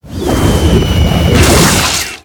hawkdive.wav